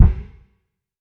TC Kick 07.wav